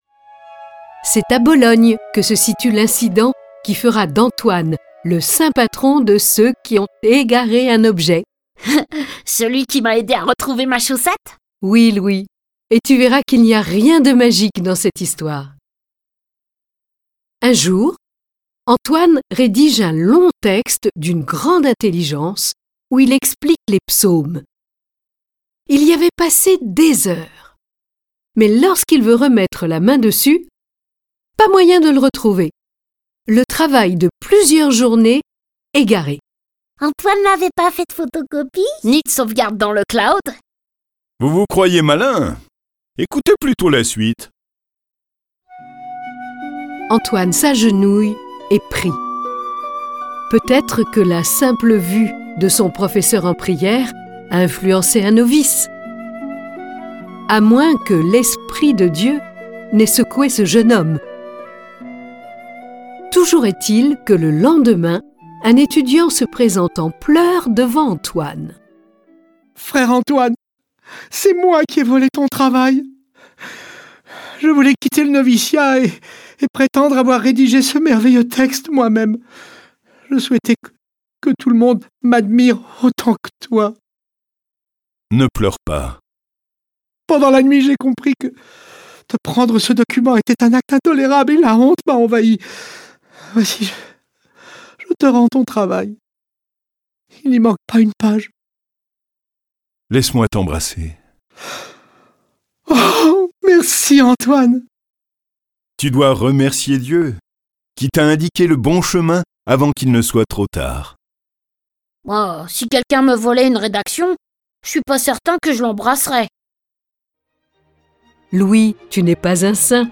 Diffusion distribution ebook et livre audio - Catalogue livres numériques
Cette version sonore de la vie de saint Antoine de Padoue est animée par 8 voix et accompagnée de près de quarante morceaux de musique classique.